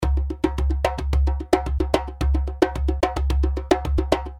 Djembe bundle - 286 loops
Explore 286 authentic Djembe loops, professionally recorded at tempos ranging from 90 to 140 BPM.
A massive collection of 286 authentic Djembe loops, recorded with world-class precision to deliver unmatched warmth, depth, and clarity.
Each rhythm captures the heartbeat of Africa, blended with subtle Arabic and Brazilian influences for a truly global percussive experience.
🎵 Studio-Grade Sound: Captured with a vintage Neumann U87 and AKG 451 stereo mics for crystal-clear, natural tone.